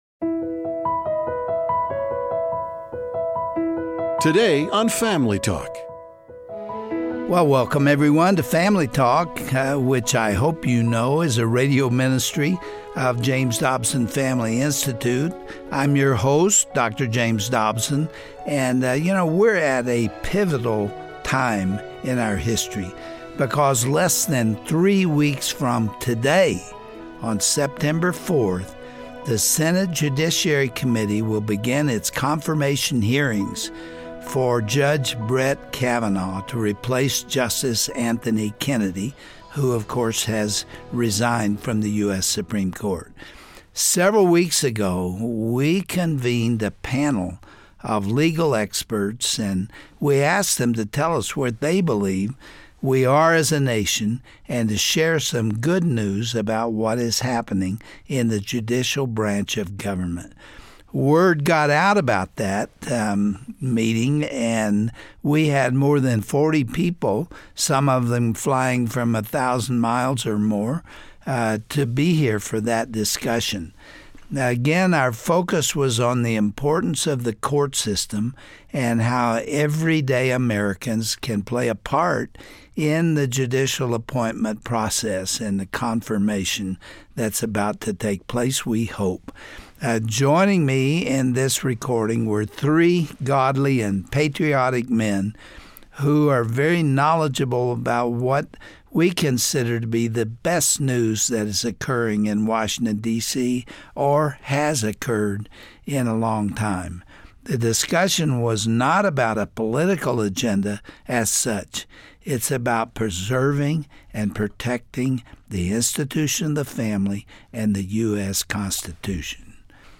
The well-being of the United States hinges on the involvement of Christians in the political process. youll hear a fascinating panel discussion Dr. Dobson took part in this summer, which focused on the importance of lower court appointments. The panel identified many religious liberty cases being fought in the courts and the change happening under the current administration.